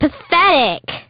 Ashley saying "Pathetic!" in WarioWare Touched